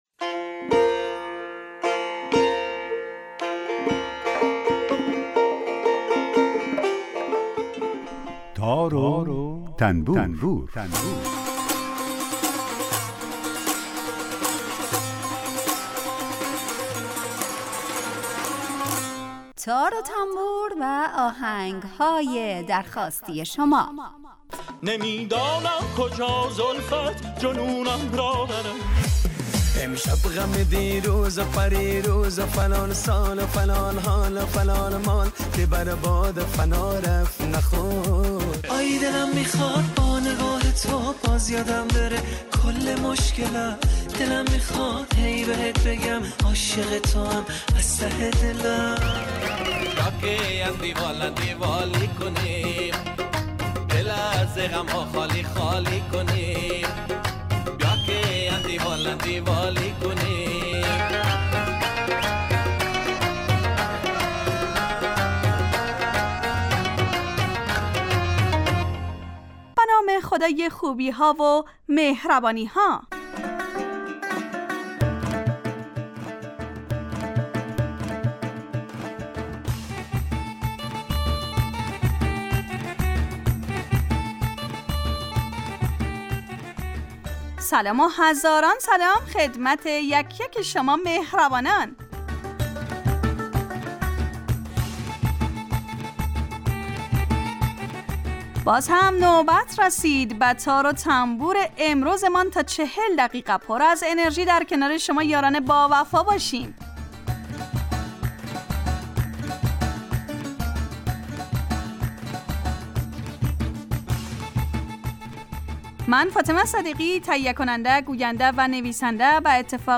برنامه ای با آهنگ های درخواستی شنونده ها
یک قطعه بی کلام درباره همون ساز هم نشر میکنیم